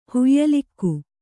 ♪ huyyalikku